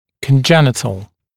[kən’ʤenɪtl][кэн’дженитл]внутренний; врождённый, природный, прирождённый